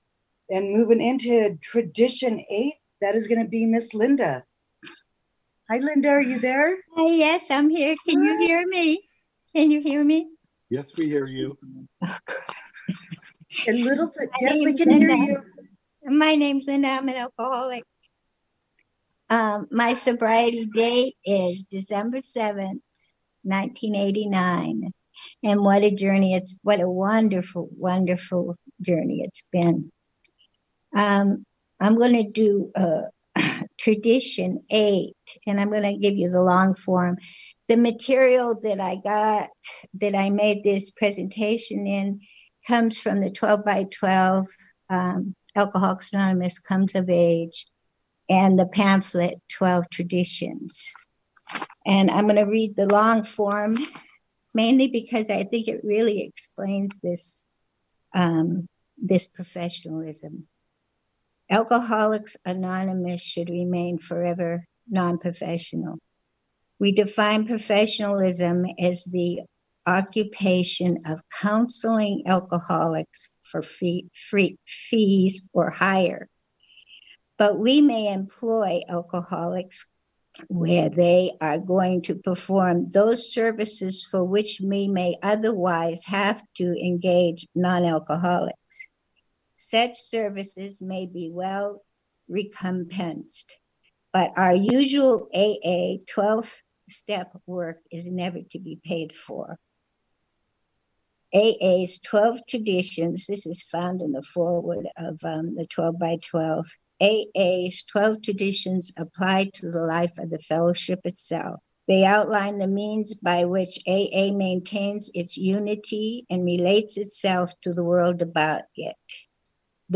CNIA DISTRICT 40 41 42 AND 43 TRADITIONS WORKSHOP